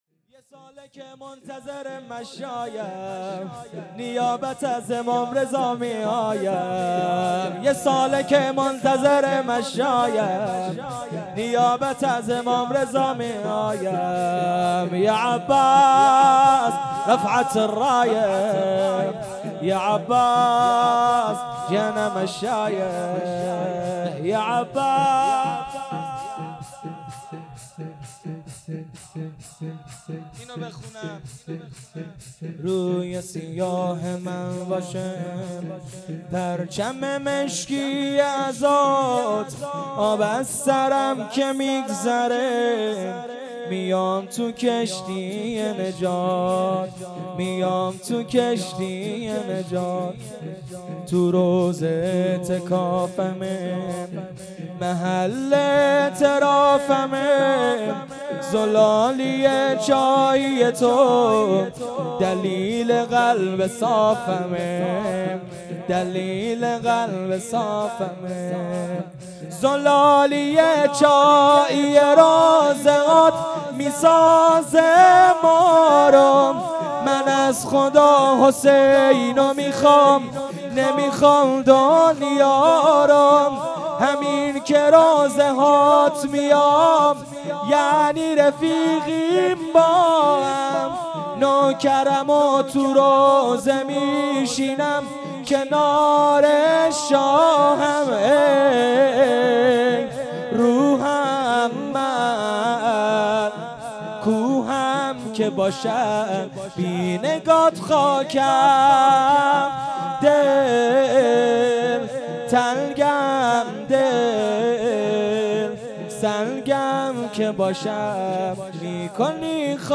جلسه هفتگی ۱۸ مهر ۱۳۹۸